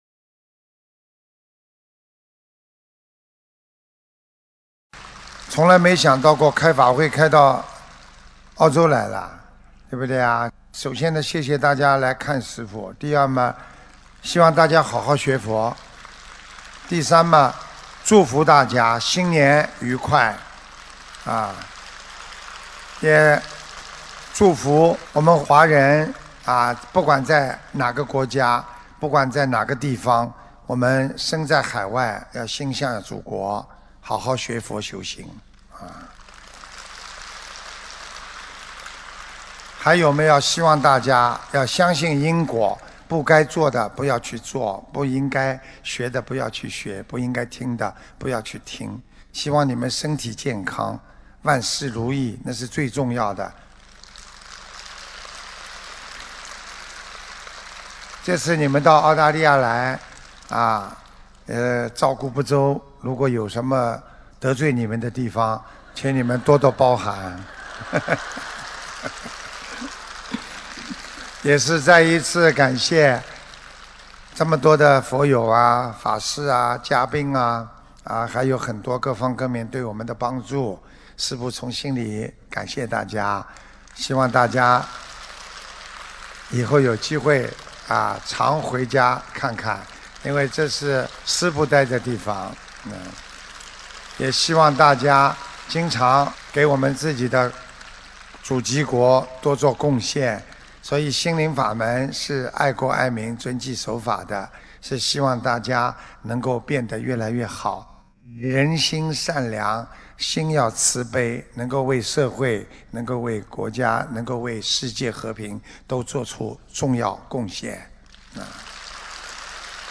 2019年1月27日悉尼法会结束语-经典感人开示